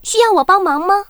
文件 文件历史 文件用途 全域文件用途 Daphne_tk_02.ogg （Ogg Vorbis声音文件，长度0.0秒，0 bps，文件大小：17 KB） 源地址:游戏语音 文件历史 点击某个日期/时间查看对应时刻的文件。